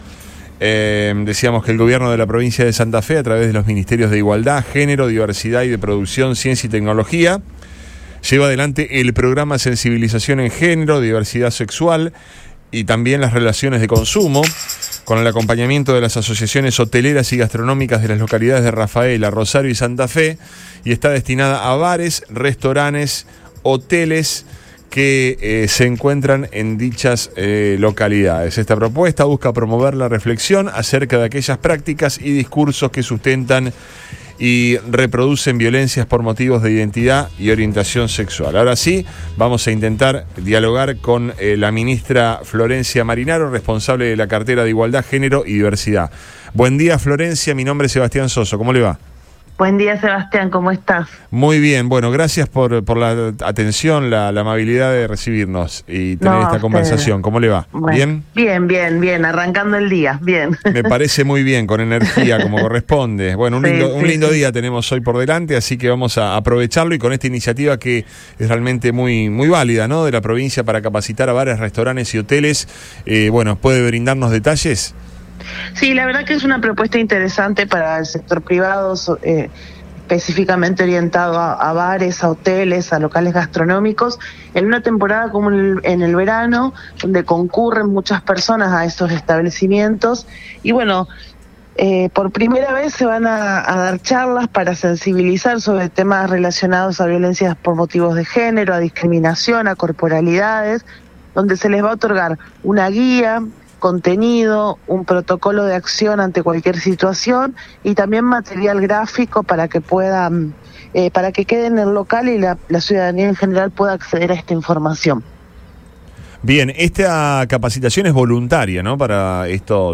En diálogo con Antes De Todo, la ministra de Igualdad, Género y Diversidad, Florencia Marinaro, explicó que “la propuesta busca promover la reflexión acerca de aquellas prácticas y discursos que sustentan y reproducen violencias por motivos de identidad, orientación sexual y/o expresión de género hacia mujeres e integrantes del colectivo LGBTIQ+ en dichos espacios”.